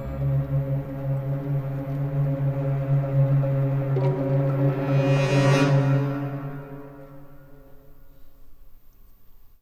Index of /90_sSampleCDs/Best Service ProSamples vol.33 - Orchestral Loops [AKAI] 1CD/Partition C/CRESCENDOS